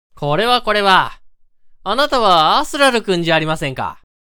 性別：男